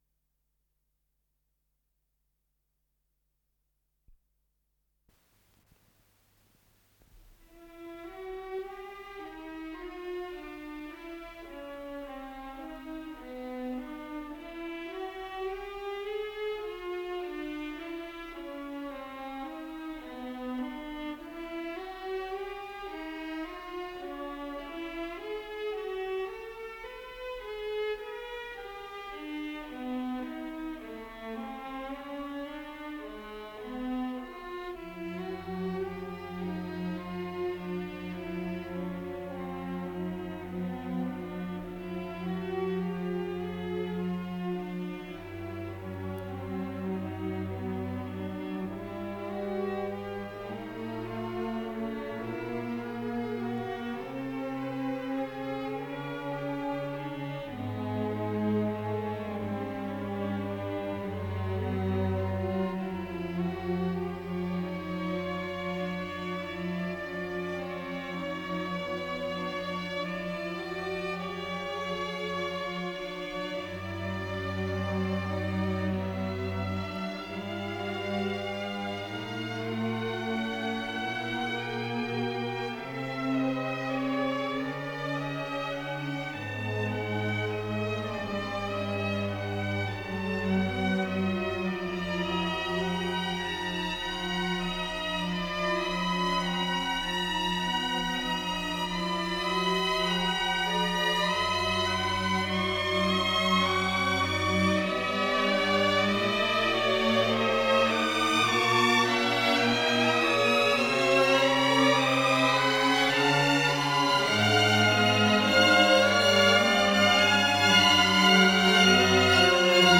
Симфоническая поэма, одночастная